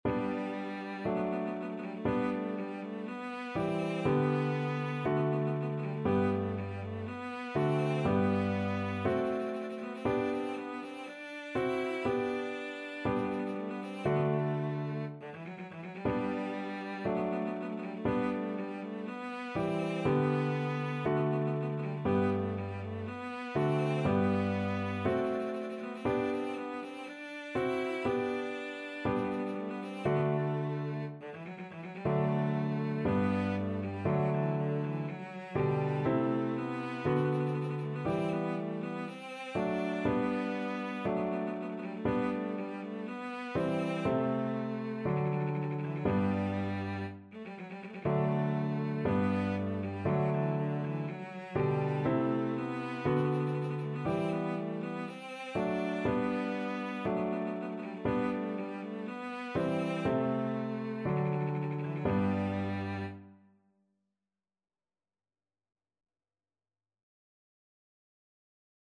Cello
G major (Sounding Pitch) (View more G major Music for Cello )
D4-E5
Classical (View more Classical Cello Music)